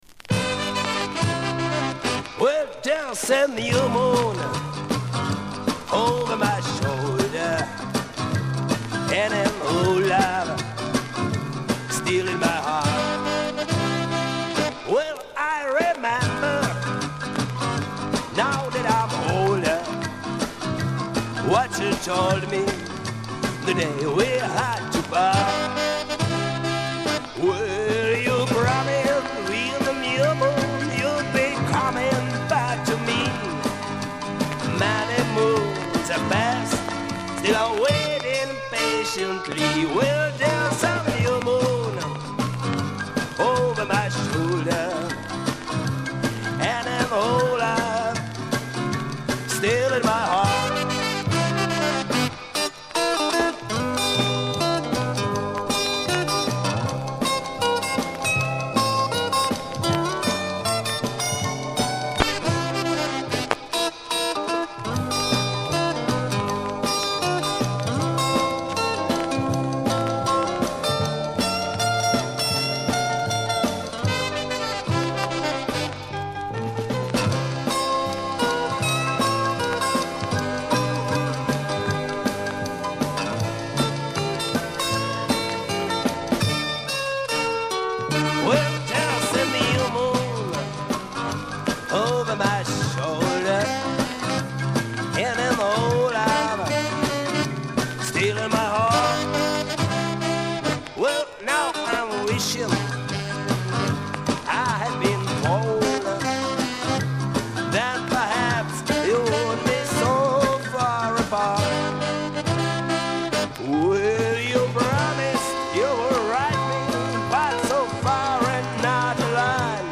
в стиле "rock 'n' roll".